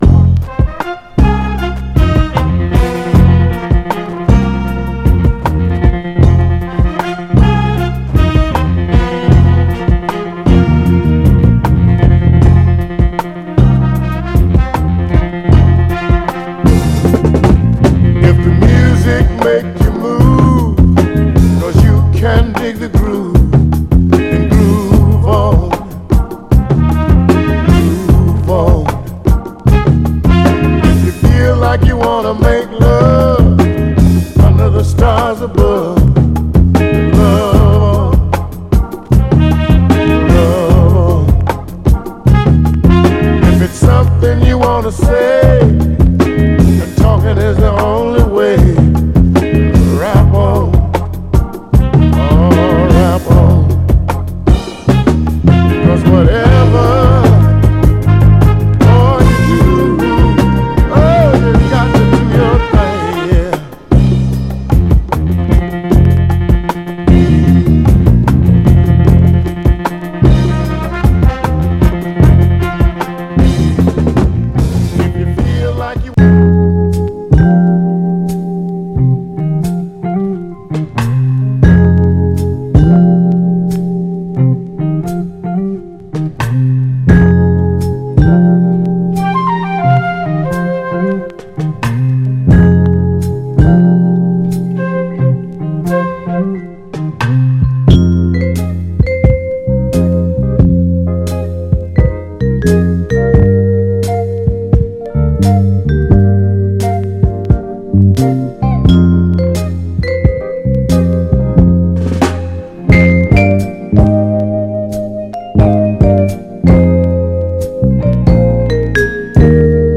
ヴァイブの効いたメロウ・インスト
※試聴音源は実際にお送りする商品から録音したものです※